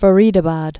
(fə-rēdə-bäd)